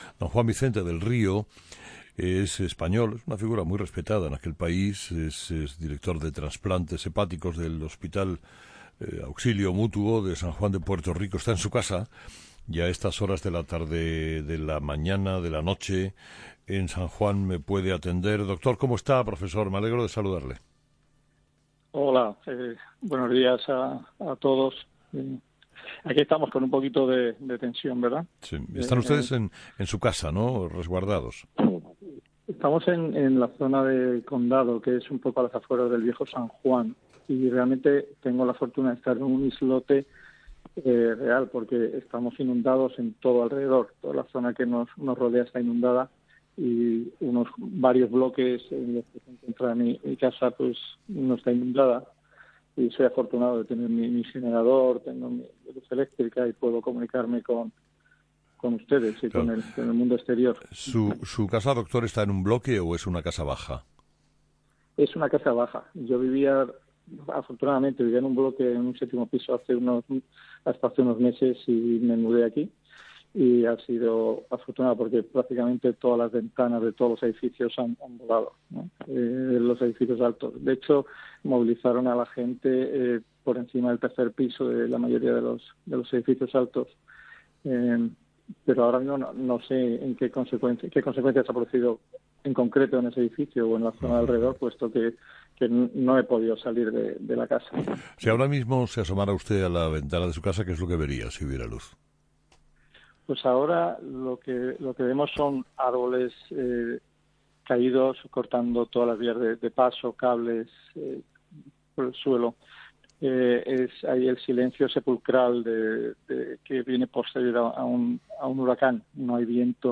Entrevistado: